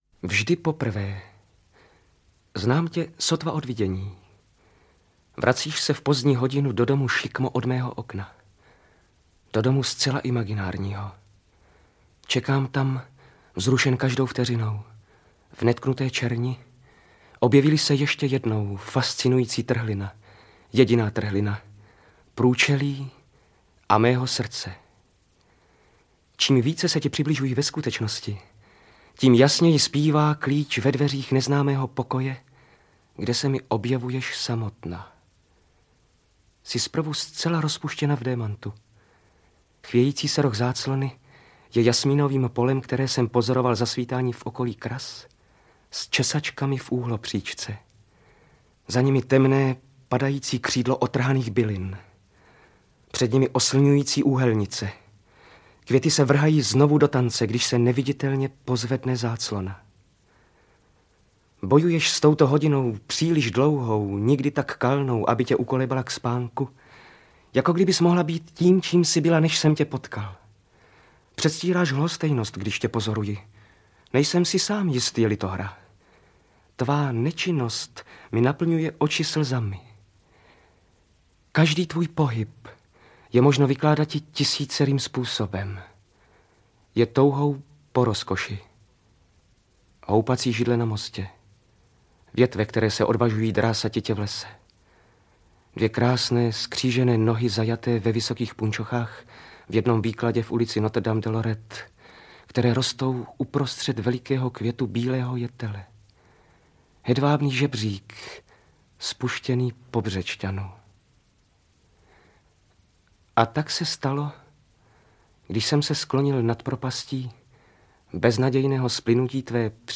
Magnetické pole audiokniha
Ukázka z knihy
• InterpretJaroslav Kepka, Luděk Munzar